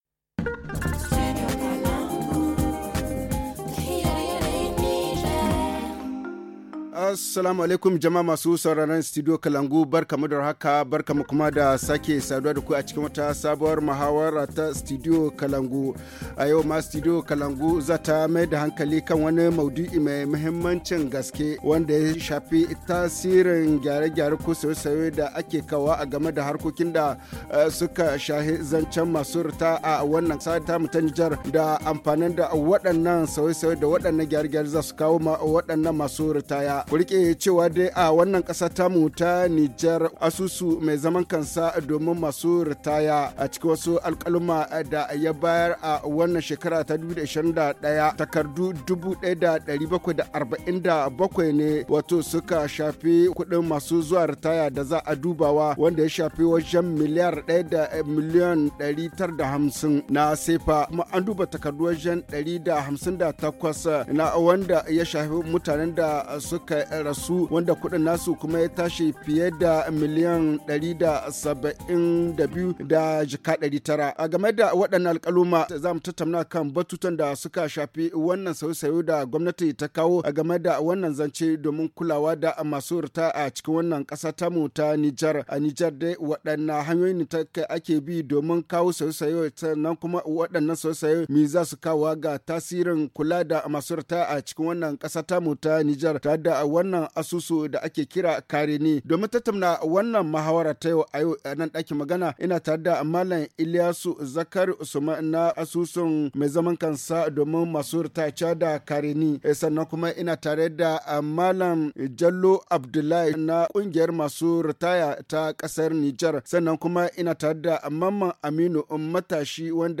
Jeune fonctionnaire. HA Le forum en haoussa https